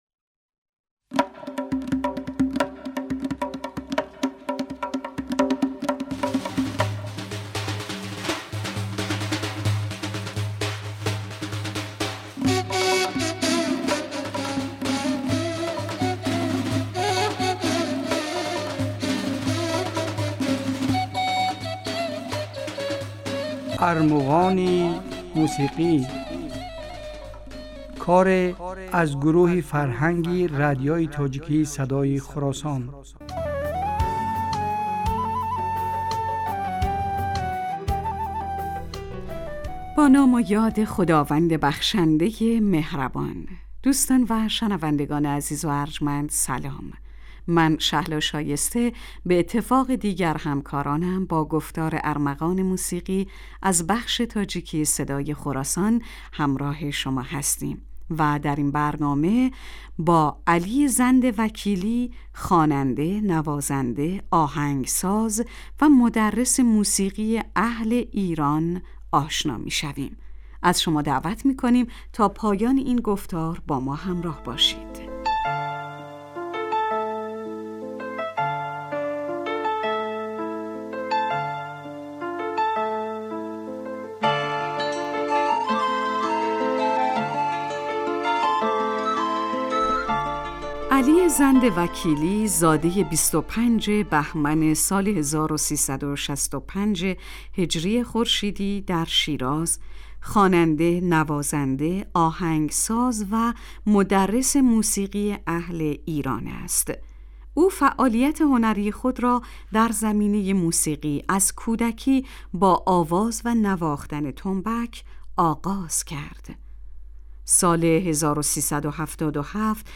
Армуғони мусиқӣ асари аз гурӯҳи фарҳанги радиои тоҷикии Садои Хуросон аст.